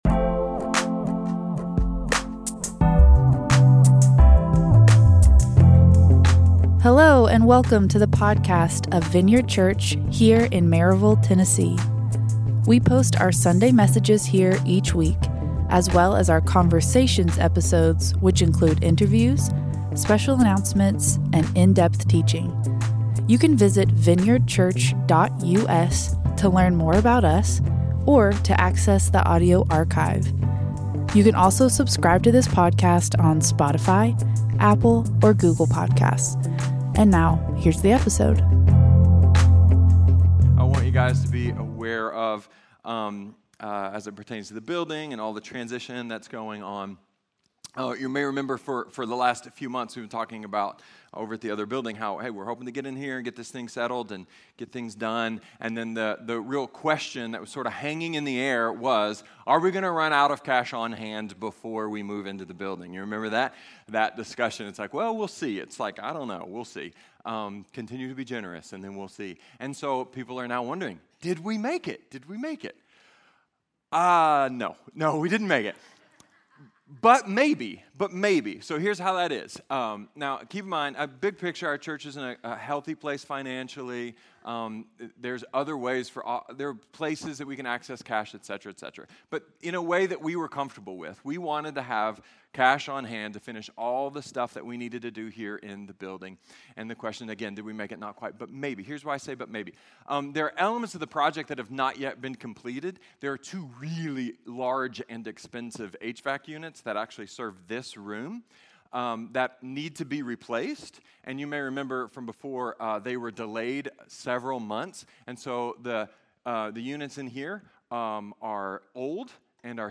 A sermon about dead air, closets, and the safest way to deal with the most dangerous things.